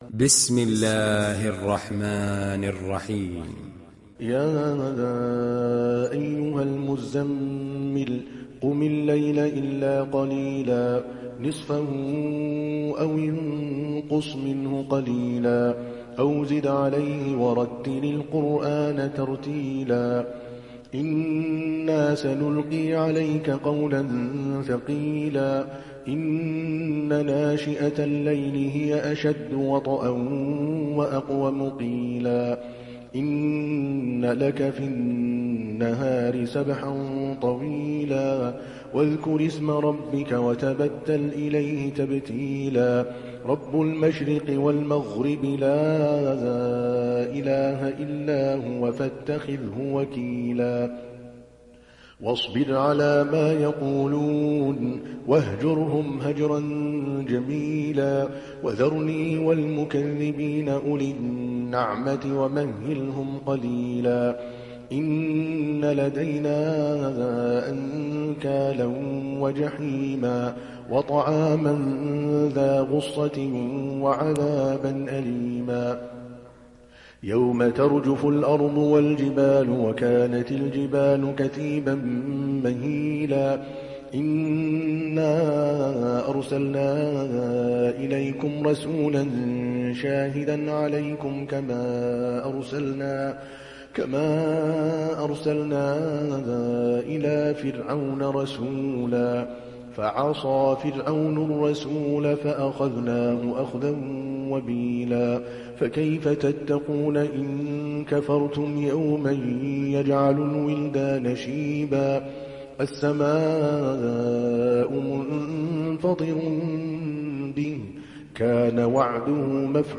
دانلود سوره المزمل mp3 عادل الكلباني روایت حفص از عاصم, قرآن را دانلود کنید و گوش کن mp3 ، لینک مستقیم کامل